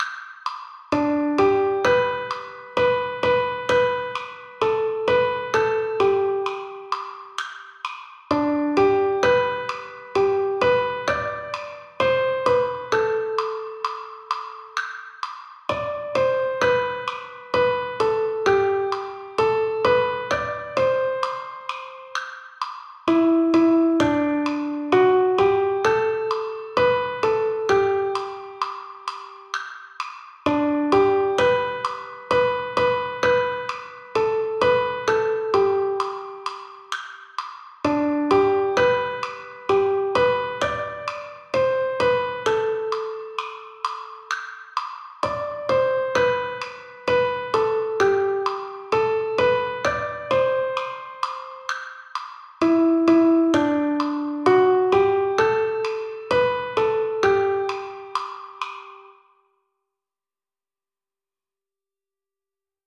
Canción tradicional de Estados Unidos.